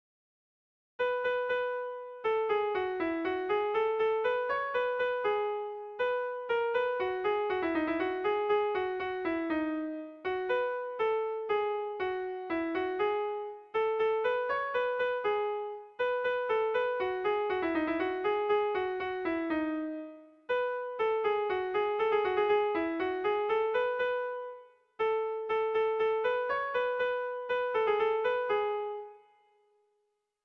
Bertso melodies - View details   To know more about this section
Erlijiozkoa
Hamabiko txikia (hg) / Sei puntuko txikia (ip)
ABA2BDE